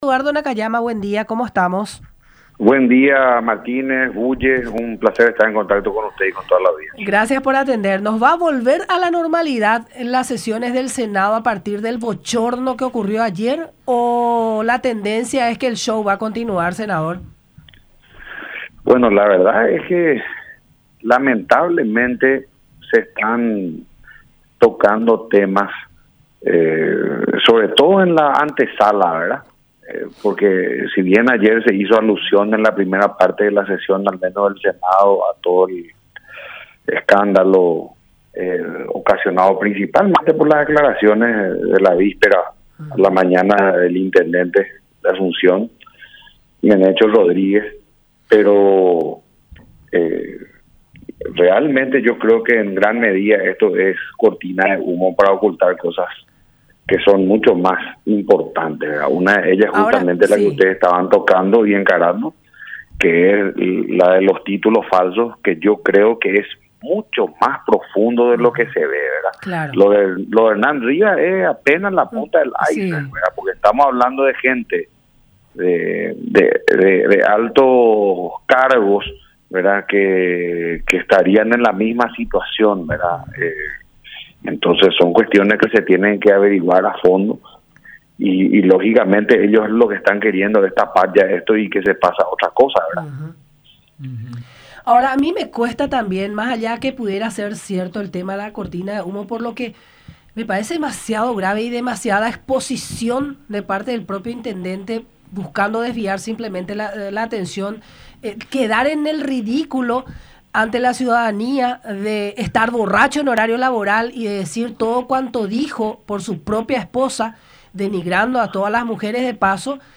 Hay que dejar en evidencia a la gente que dice ser opositora pero es más cartista”, agregó en el programa “La Unión Hace La Fuerza” por radio La Unión y Unión Tv.